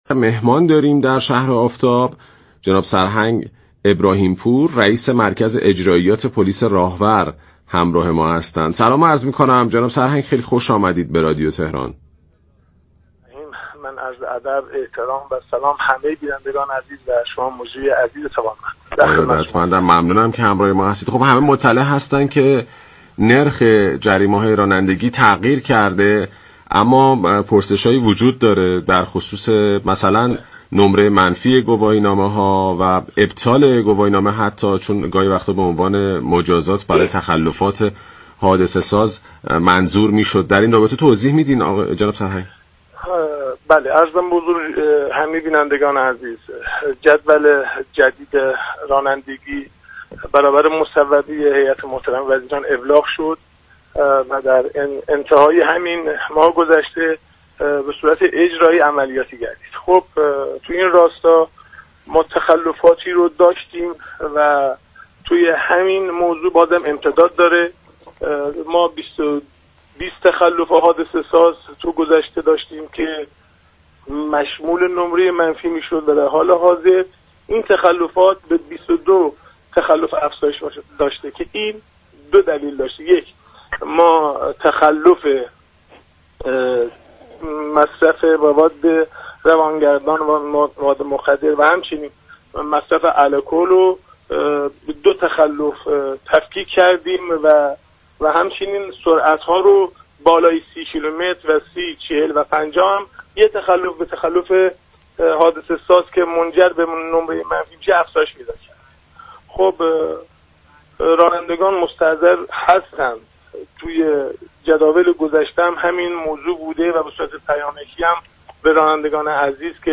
به گزارش پایگاه اطلاع رسانی رادیو تهران؛ سرهنگ محمدحسین ابراهیم پور رئیس مركز اجرائیات پلیس راهورفراجا در گفتگو با برنامه شهر آفتاب با اشاره به تغییرات جدید نرخ جریمه‌های رانندگی گفت: جدول جدید رانندگی براساس مصوبه هیات وزیران ابلاغ و در انتهای تیرماه عملیاتی شد.